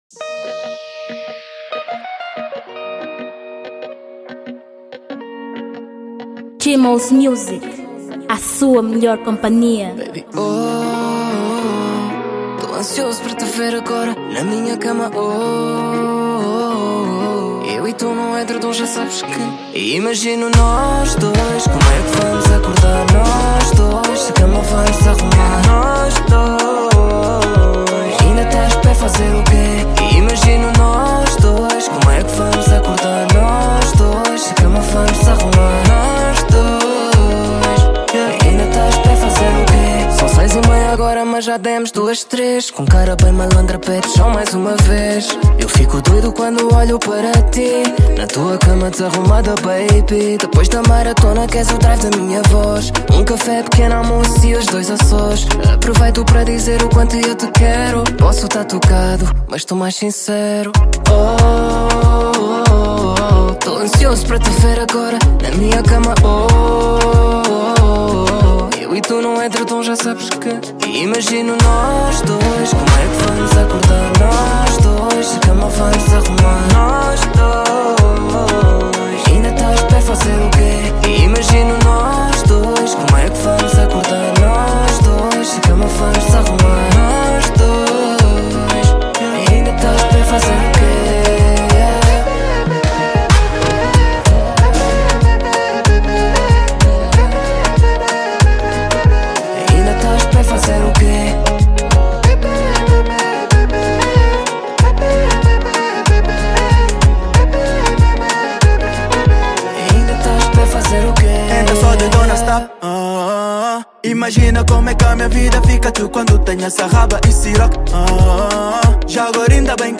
Uma música do gênero Dancehall com um ótimo conteúdo.